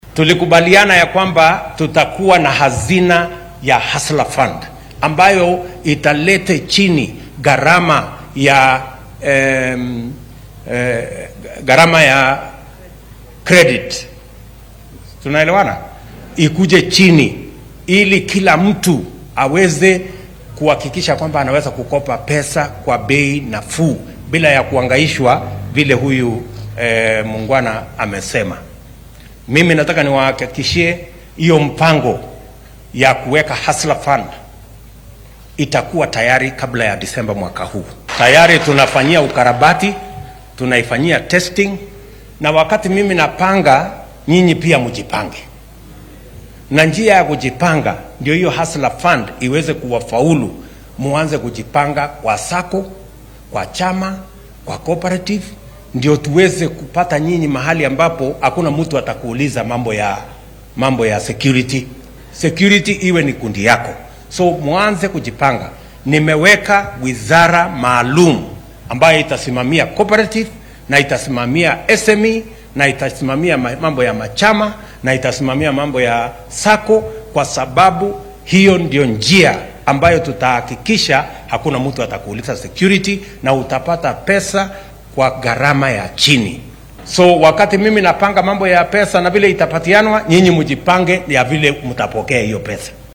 Madaxweynaha dalka William Ruto ayaa sheegay in maamulkiisa uu inta aan la gaarin bisha 12-aad ee sanadkan dhameystiri doono qorshaha lacagaha amaahda ah lagu siinaya ganacsatada yaryar. Arrintan ayuu sheegay xilli uu ismaamulka Kirinyaga ee gobolka bartamaha dalka uu xariga uga jaray biyo xireenka Thiba Dam. Waxaa uu sheegay in sanduuqa Hustler Fund ay ganacsatada ka deynsan doonaan lacago ay qiimo yar oo dulsaar ah ku helayaan.